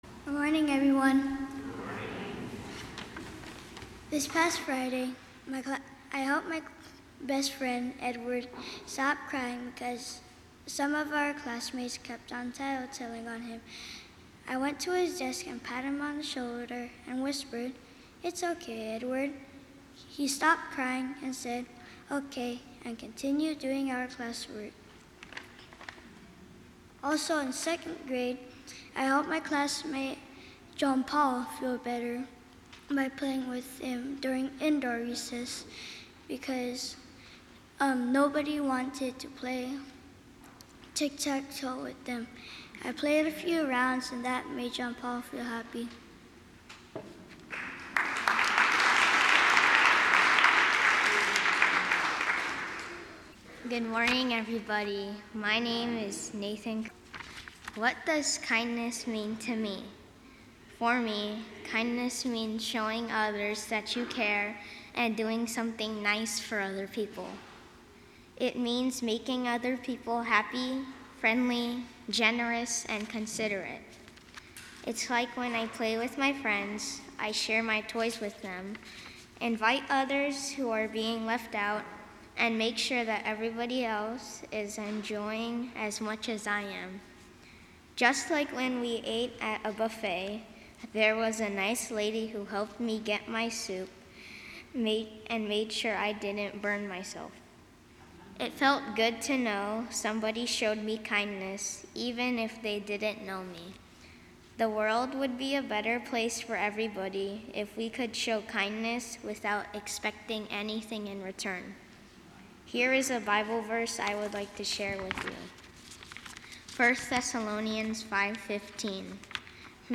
Children’s Sabbath 2018 – 9:30
Children